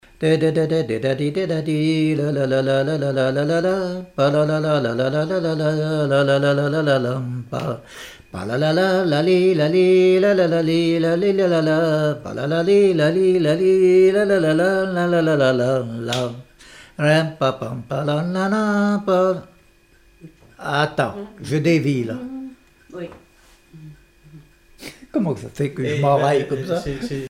danse : quadrille : chaîne anglaise
Témoignage comme joueur de clarinette
Pièce musicale inédite